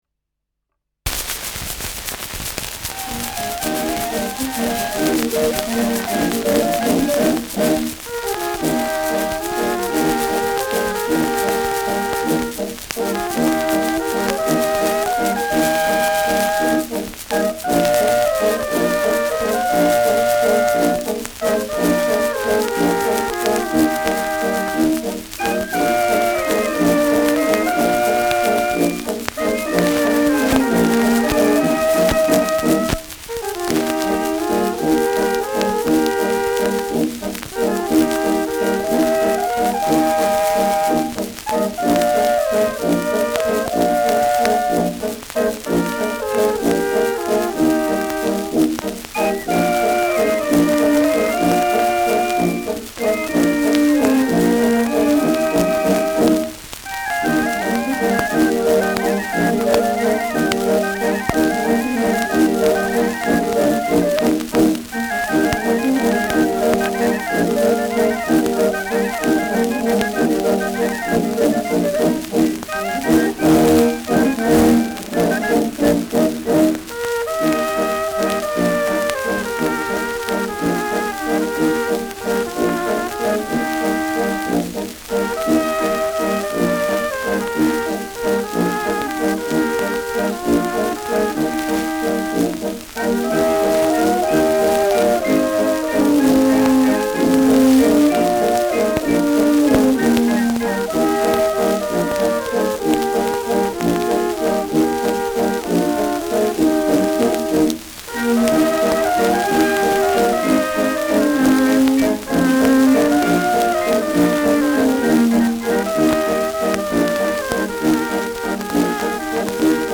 Schellackplatte
präsentes Rauschen : Knistern
Die sentimentale Melodie wird hier zweistimmig von Trompeten (nach Noten) gespielt, im „Nachspiel“ liegt die Melodie bei den Klarinetten, oktaviert von tiefem Blech.